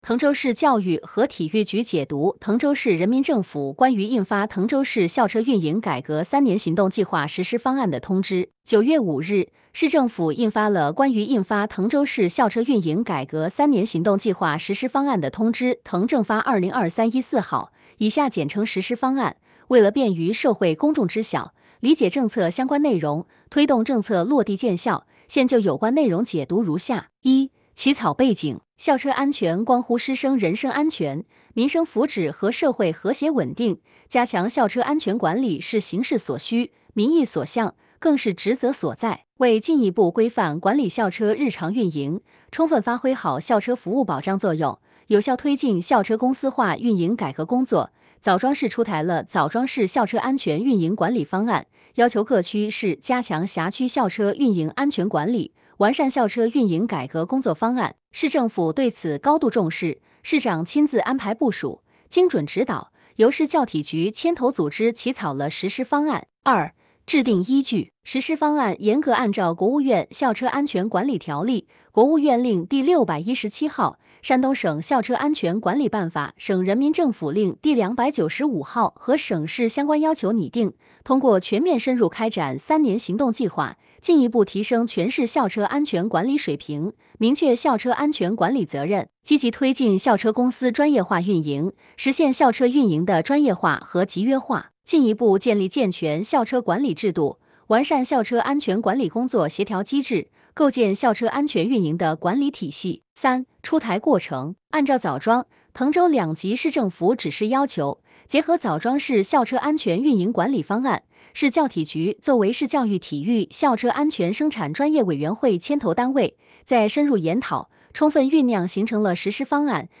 主题分类： 音频解读